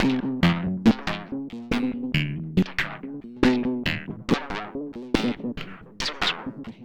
Index of /90_sSampleCDs/Sample Magic - Transmission-X/Transmission-X/transx loops - 140bpm